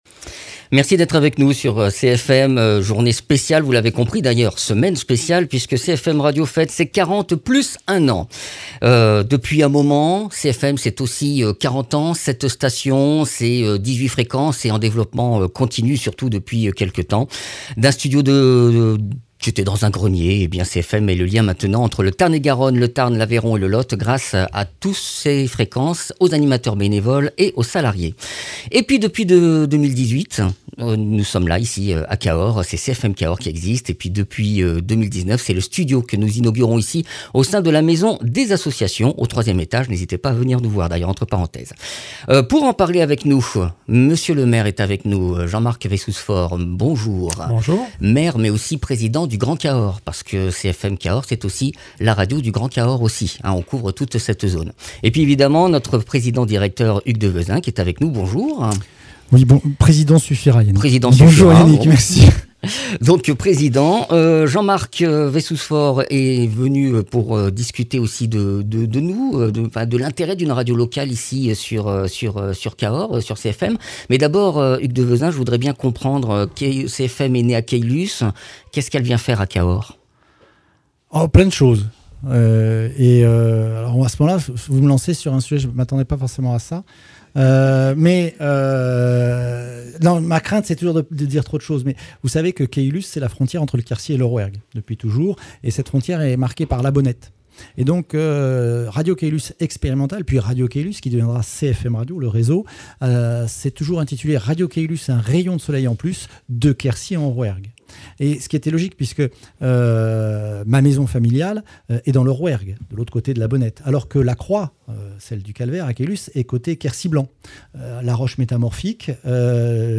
Invité(s) : Jean Marc Vayssouze-Faure, maire de Cahors.